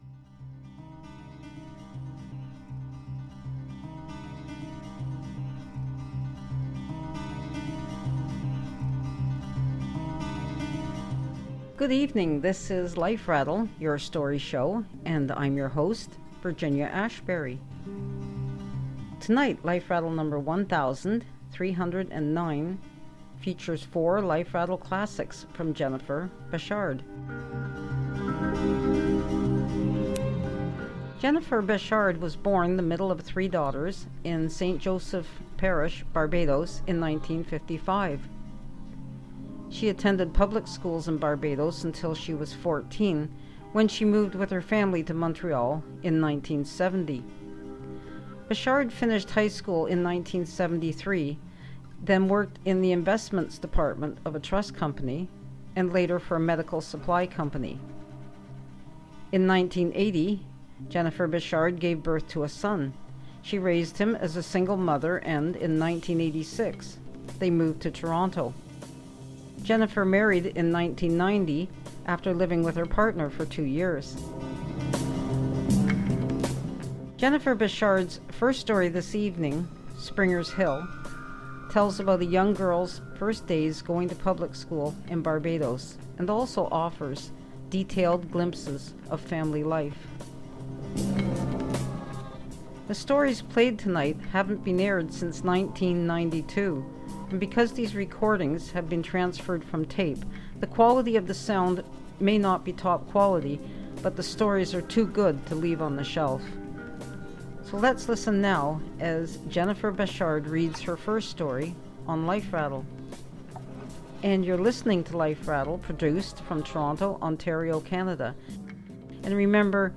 Because these recordings have been transferred from audio cassette, the quality of the sound may not be top quality, but the stories are too good to leave on the shelf.